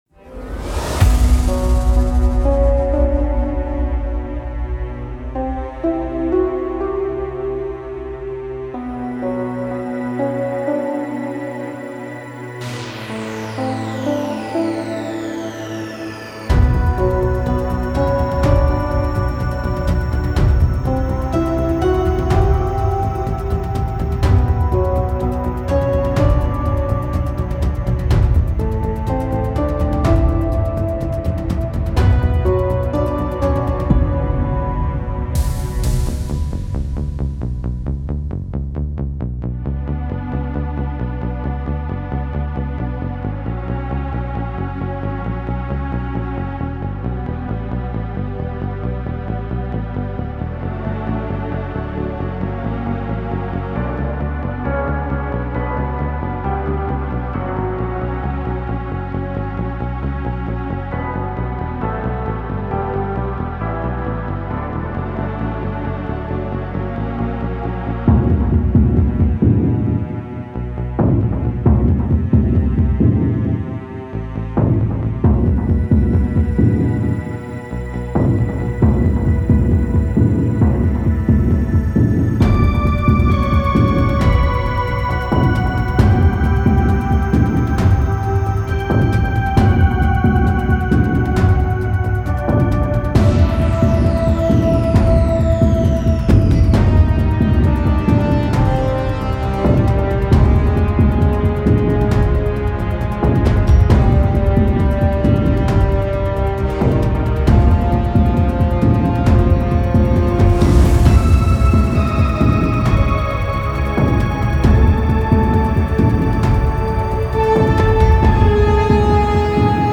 Mysterious, evolving score bed